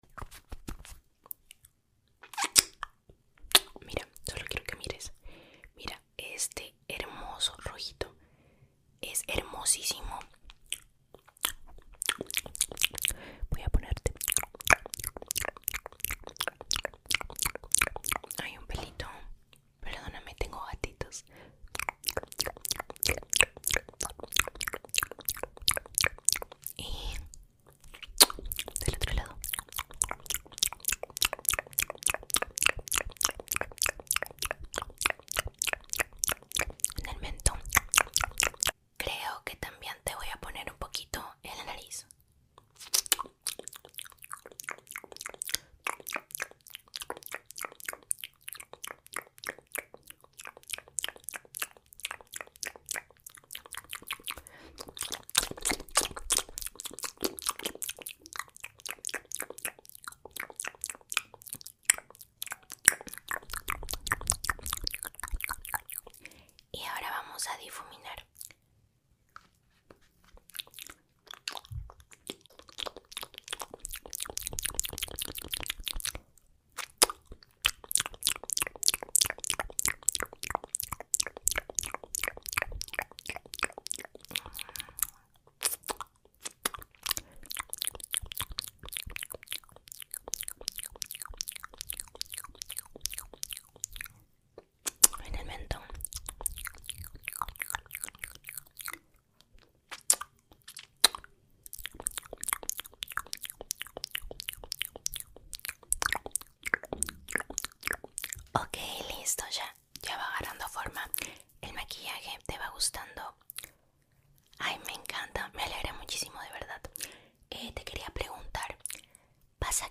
ASMR MOUTH SOUNDS 👅 sound effects free download